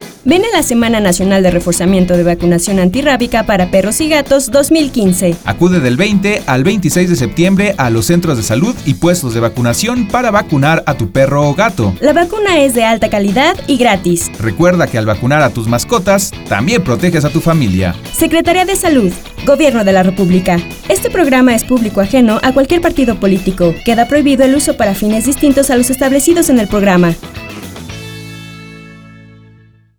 PerifoneoSNRVACyF2015.wav